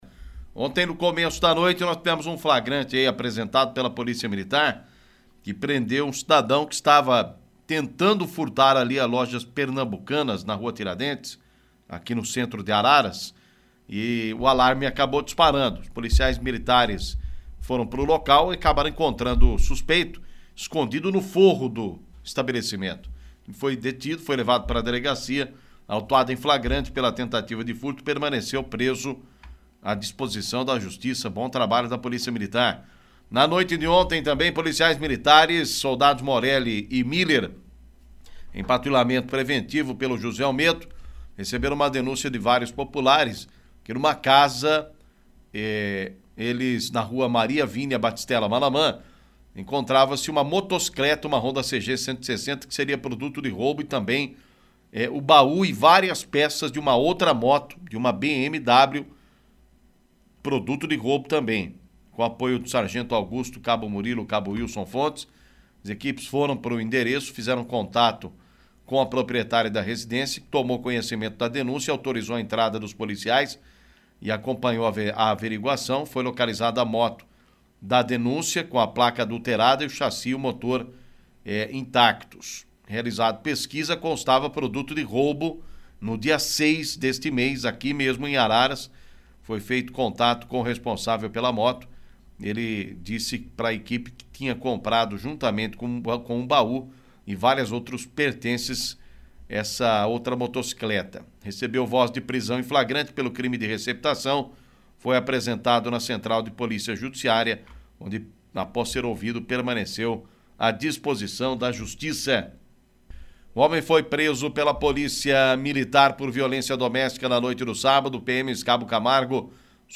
Polícia